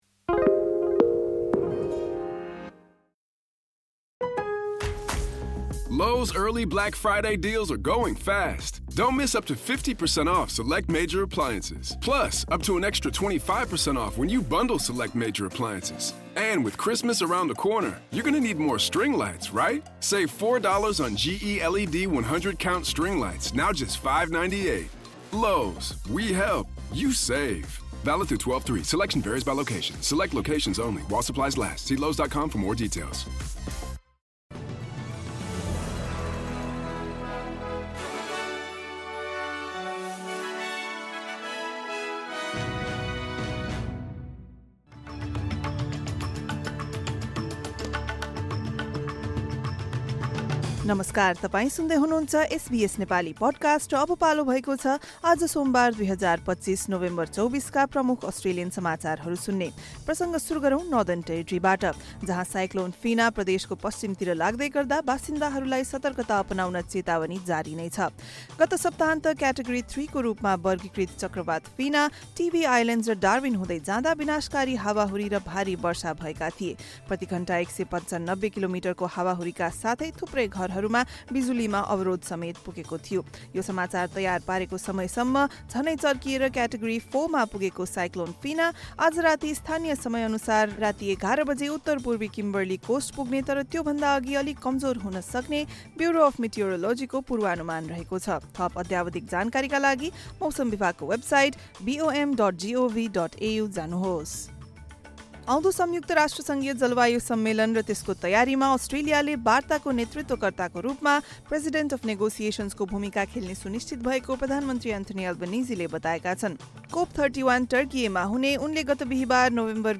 एसबीएस नेपाली प्रमुख अस्ट्रेलियन समाचार: सोमवार, २४ नोभेम्बर २०२५